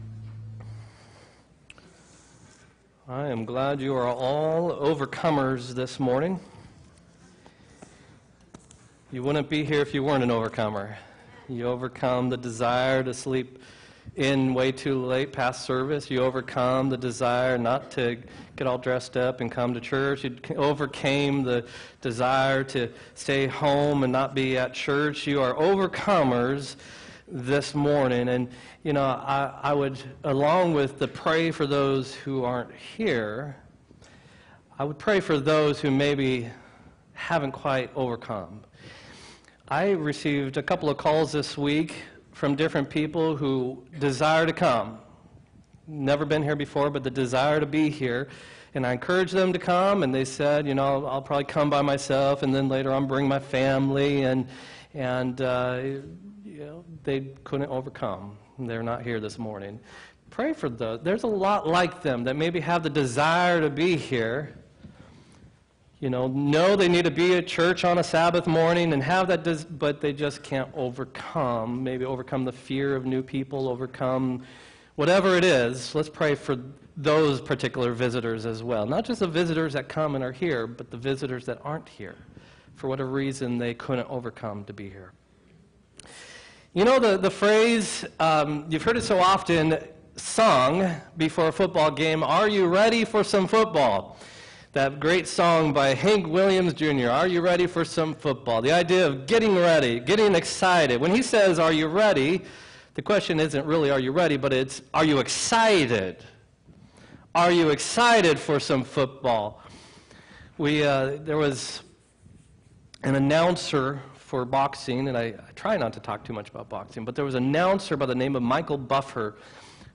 7-20-19 sermon